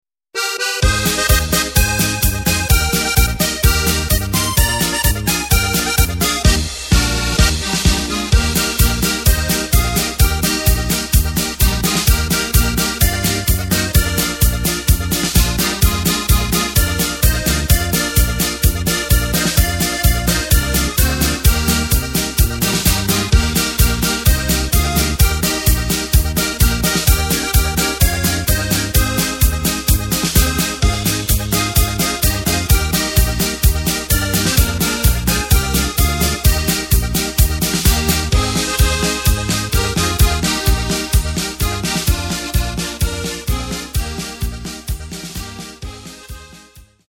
Tempo:         128.20
Tonart:            Eb
Schweizer MarschLied!